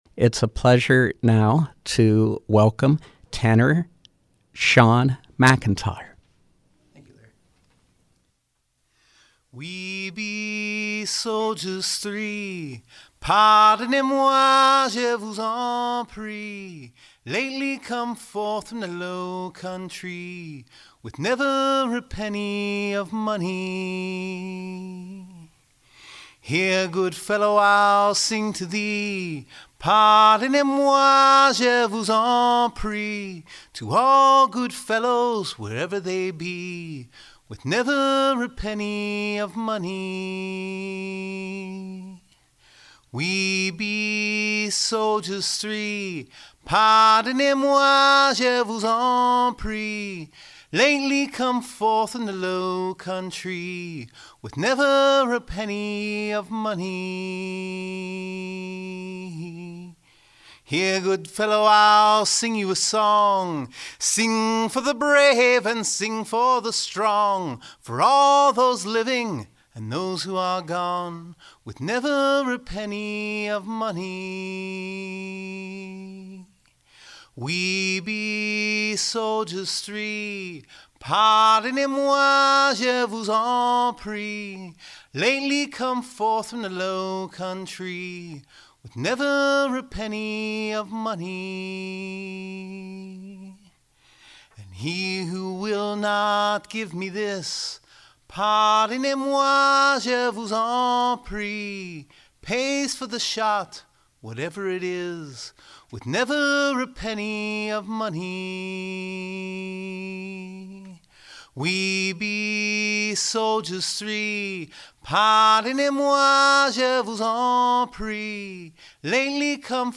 Live Music
A cappella singer
performs a selection of traditional folk tunes and sea shanties.